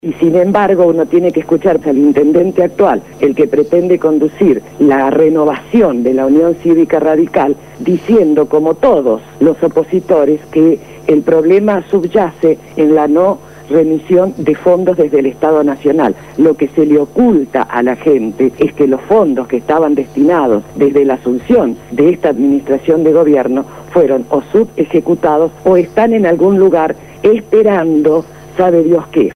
habló esta mañana en el programa Punto de Partida de Radio Gráfica FM 89.3 sobre el panorama de la ciudad de Santa Fe luego de la tormenta de los últimos días.